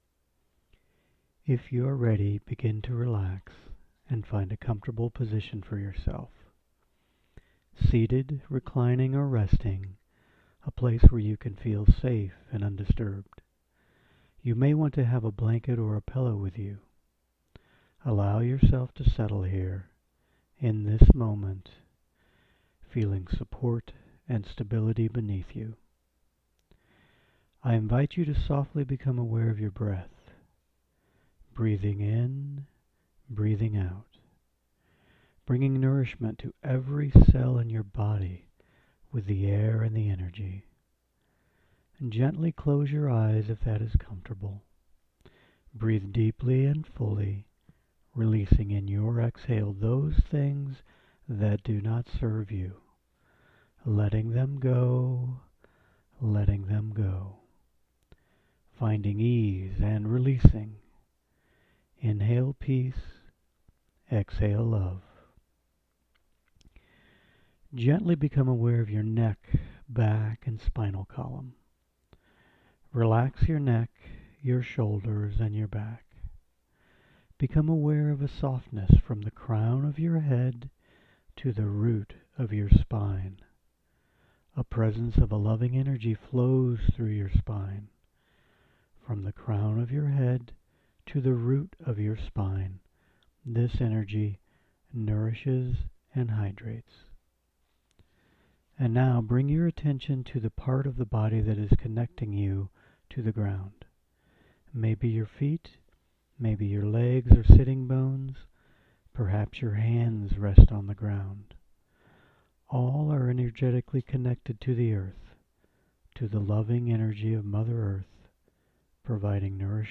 Download Yoga & Hypnosis - Grounding & Centering MP3 (Sound) Best with Earbuds or Headset